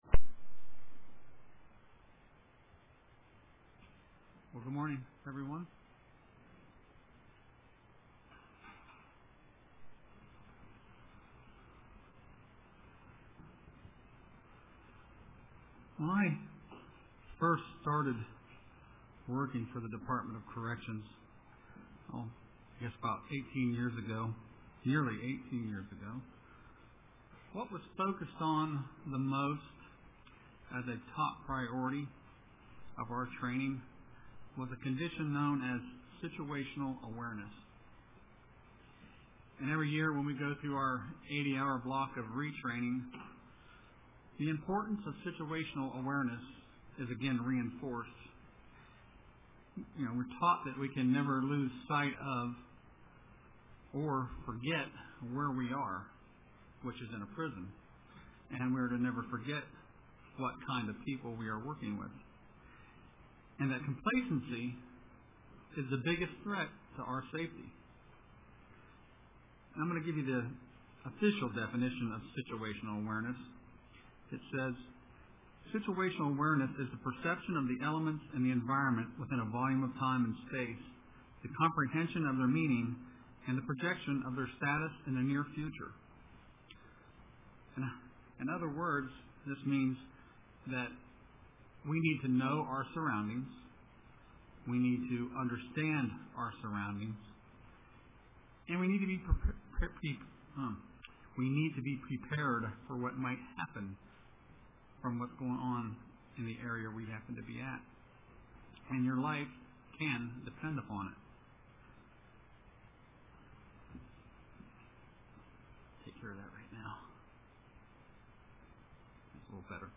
Print Normalcy Biases or Situationally Aware UCG Sermon Studying the bible?